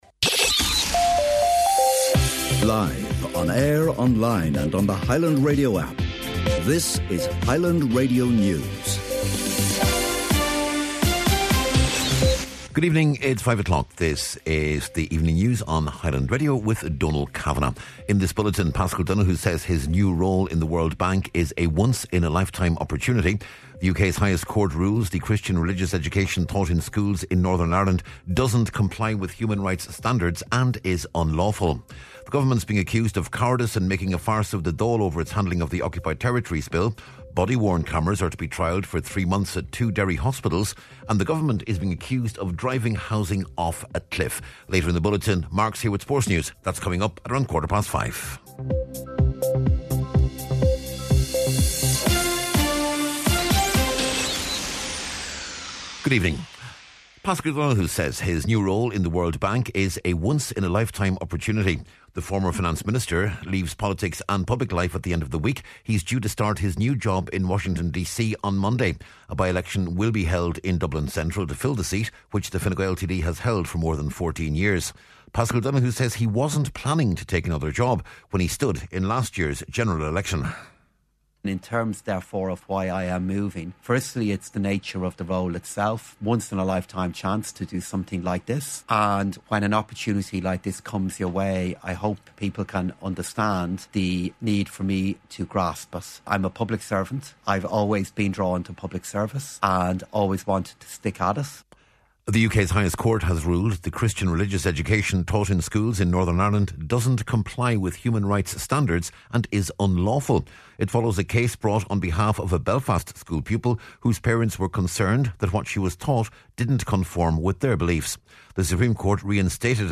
News, Sport, and Obituaries on Wednesday November 19th